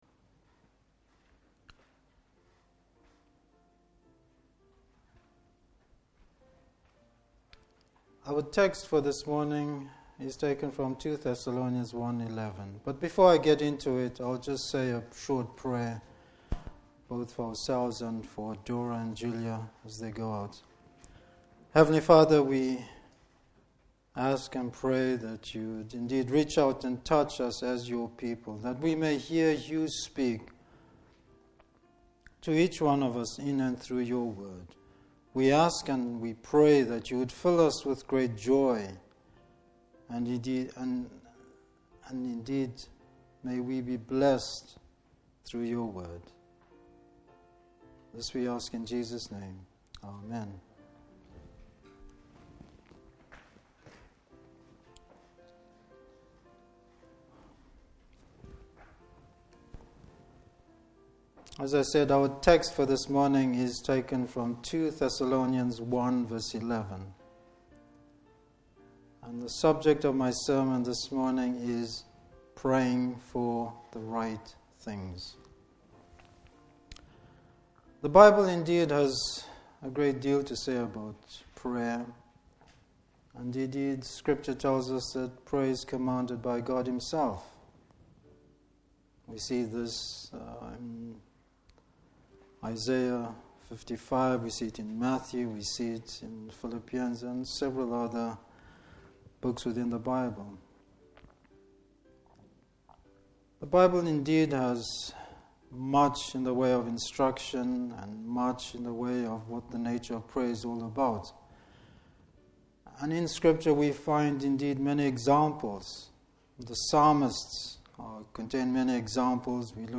Service Type: Morning Service Bible Text: 2 Thessalonians 1:11.